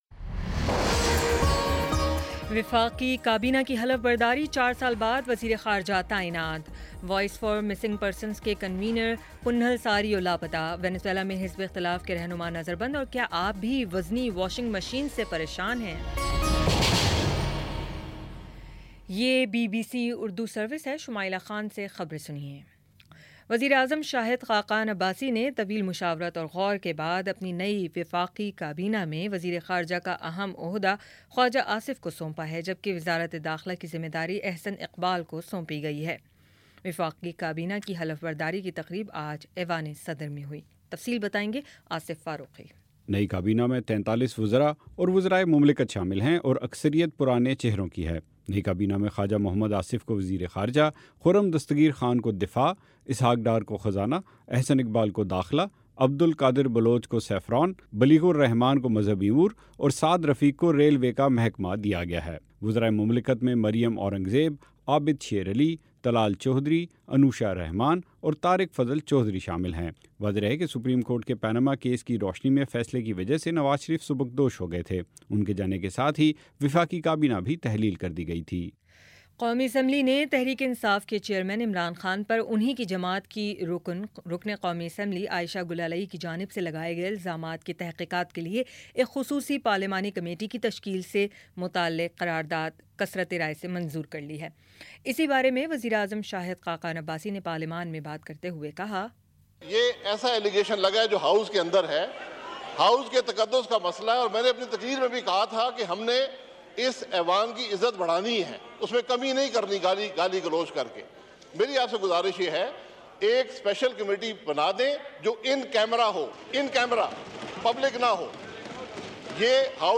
اگست 04 : شام چھ بجے کا نیوز بُلیٹن